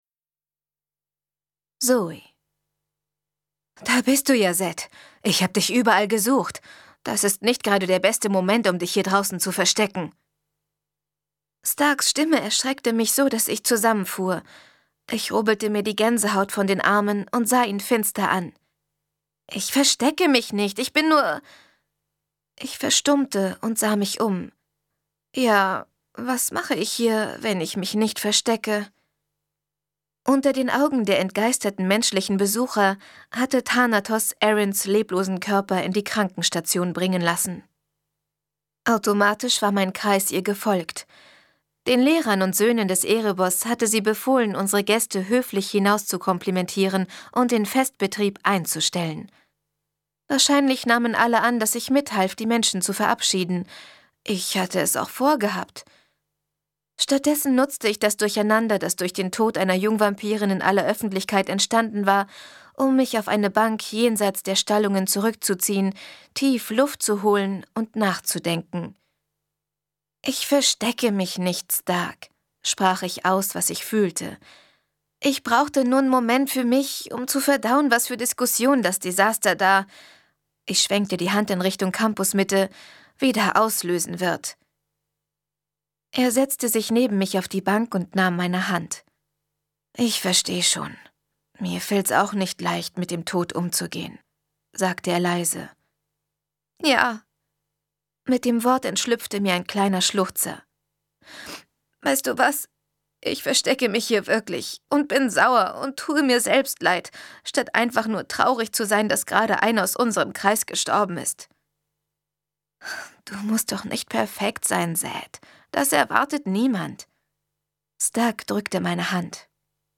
Hörprobe House of Night - Entfesselt 11.